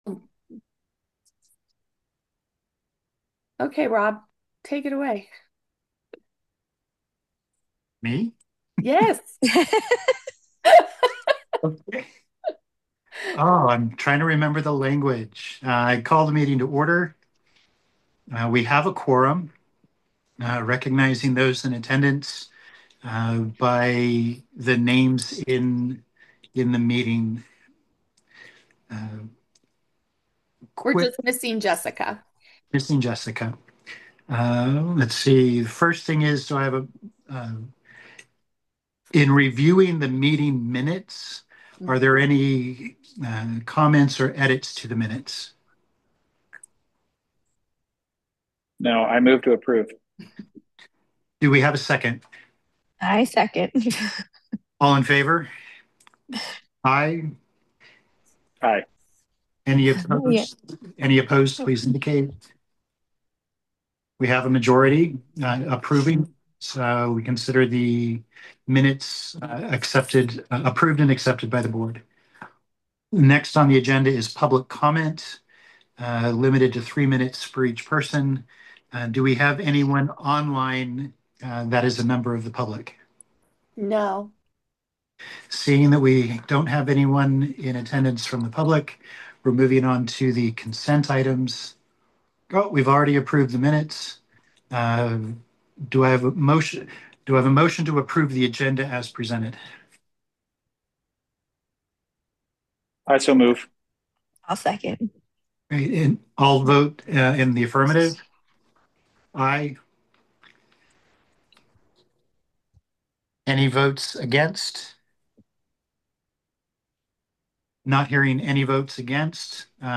Board Meeting